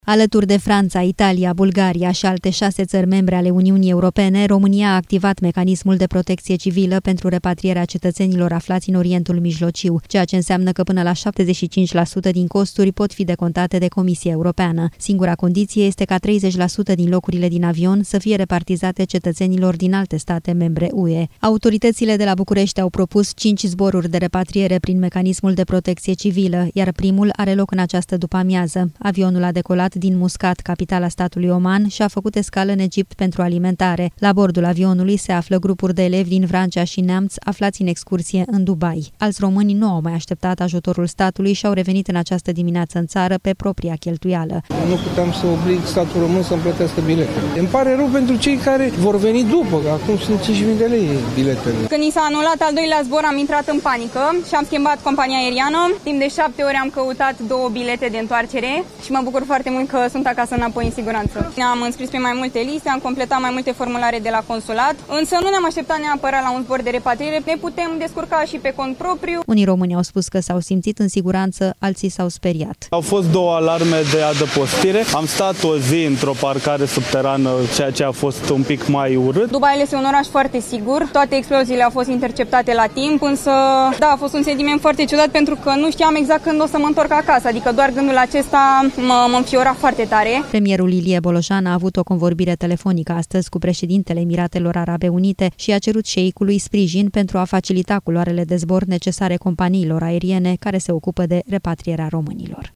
Le-au povestit jurnaliștilor aflați pe aeroportul Otopeni că au căutat mai multe zile bilete de avion.